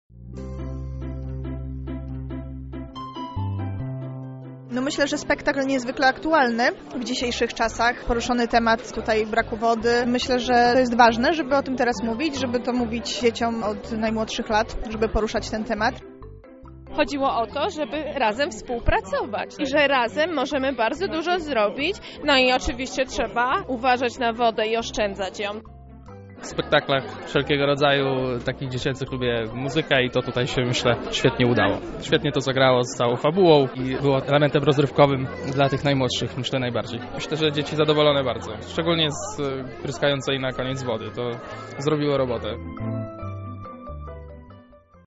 Widzowie podczas premiery docenili między innymi przesłanie jakie za sobą niesie ta historia: